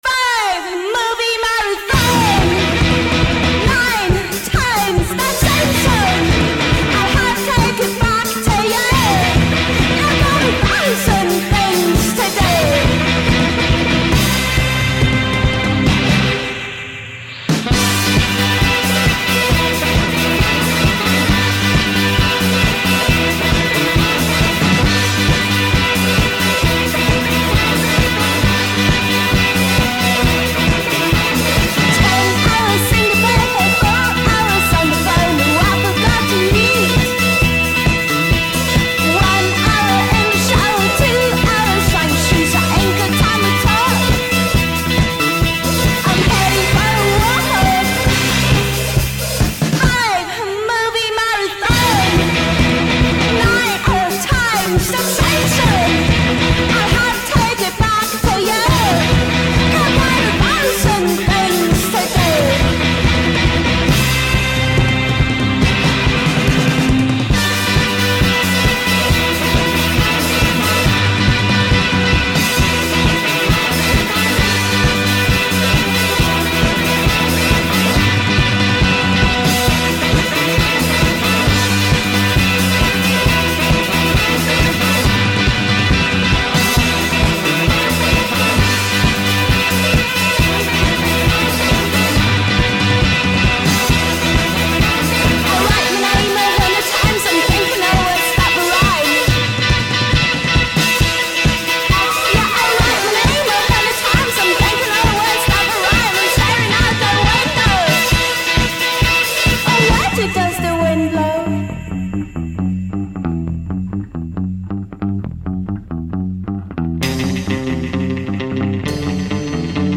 But all played in a juvenile fashion.